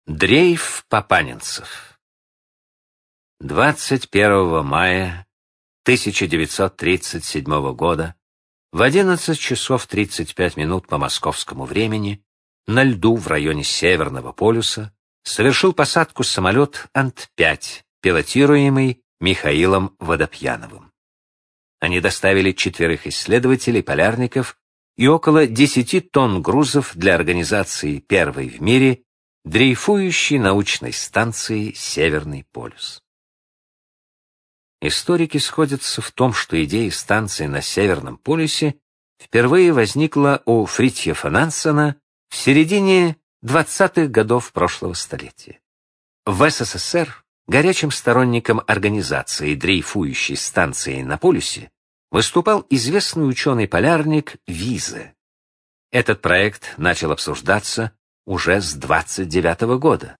Аудиокнига Великие одиссеи | Библиотека аудиокниг